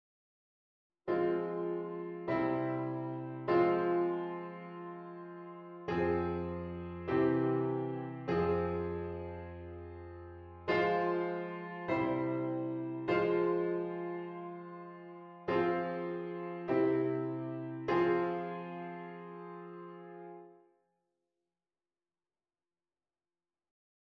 verbinding I - V - I (harmonische verbinding)